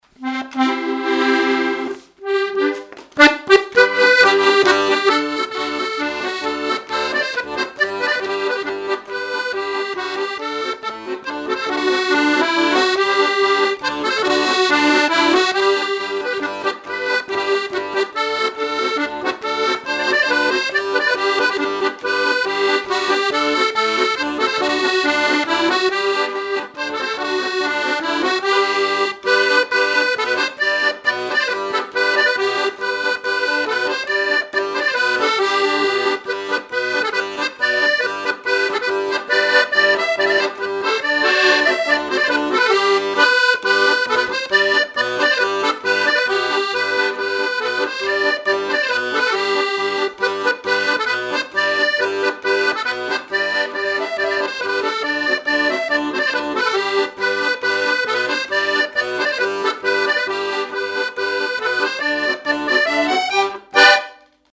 danse : marche
Pièce musicale inédite